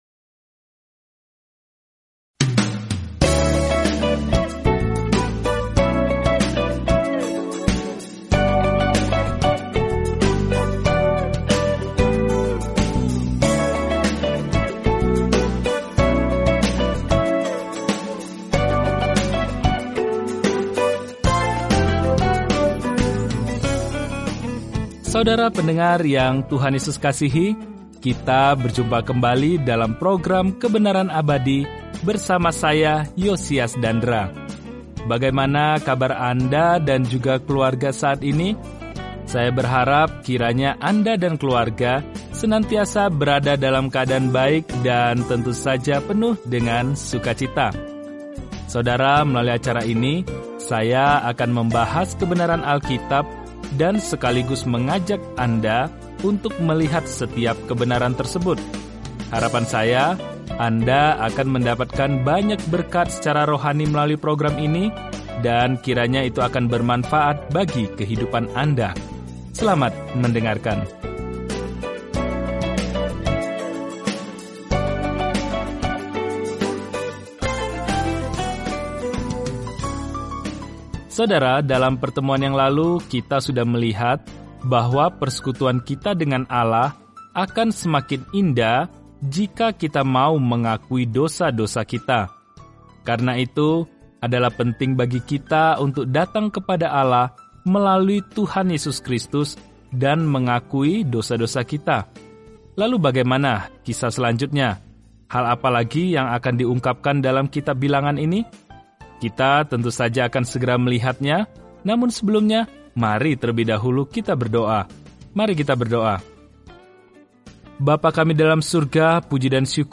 Firman Tuhan, Alkitab Bilangan 20 Hari 12 Mulai Rencana ini Hari 14 Tentang Rencana ini Dalam kitab Bilangan, kita berjalan, mengembara, dan beribadah bersama Israel selama 40 tahun di padang gurun. Jelajahi Numbers setiap hari sambil mendengarkan studi audio dan membaca ayat-ayat tertentu dari firman Tuhan.